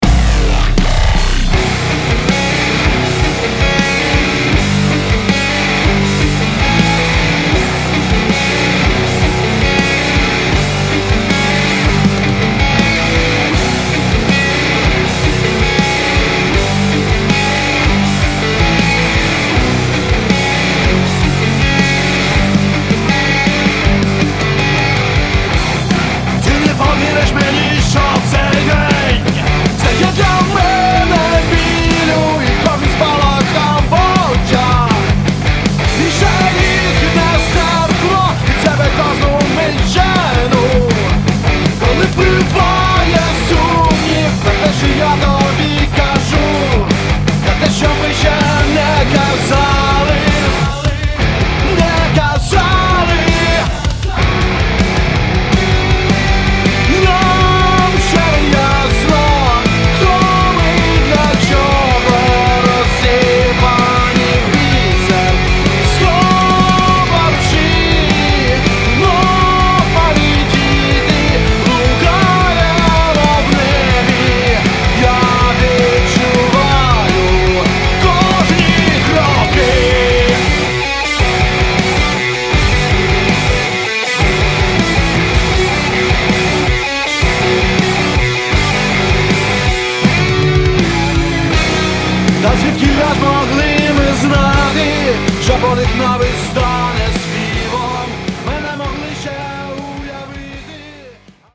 Жду советов по сведению (альтернатива)